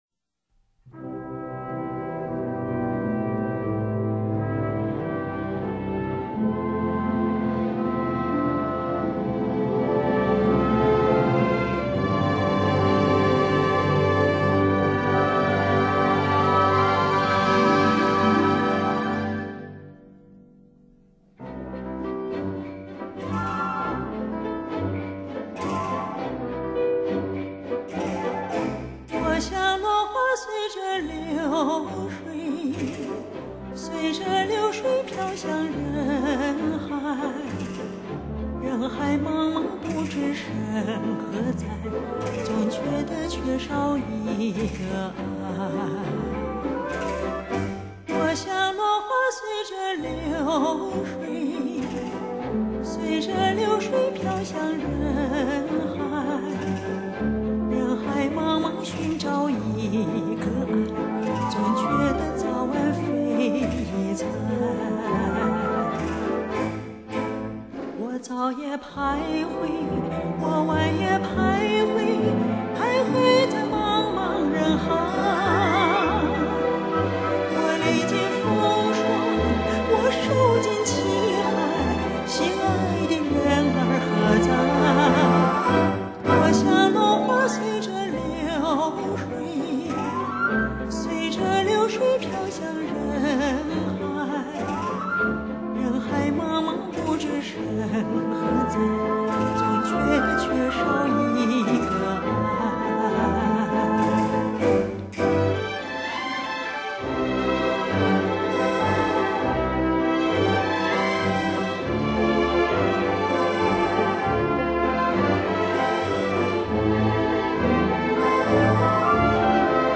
并以管弦乐的手法重新编曲
而三位歌者的嗓音历经岁月的冶炼，益发醇厚动人，相信是欲重灸这些老唱将歌声的最好选择。